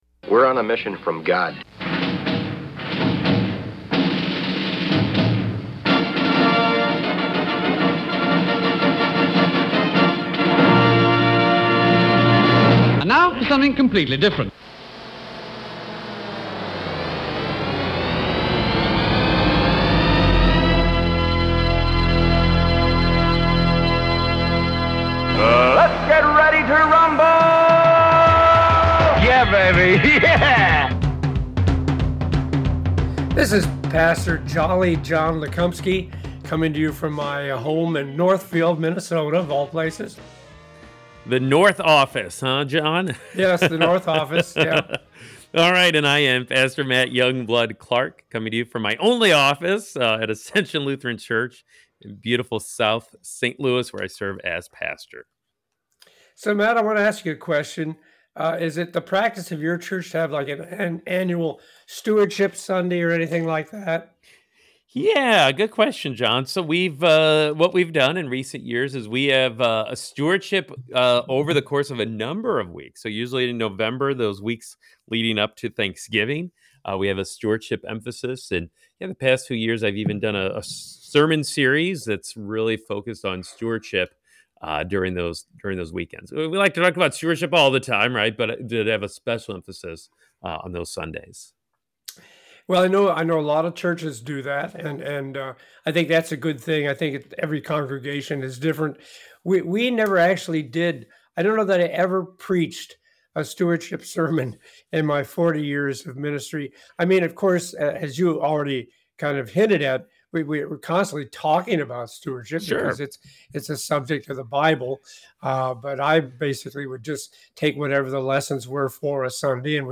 Wrestling With the Basics (WWB) a humorous approach to Bible Study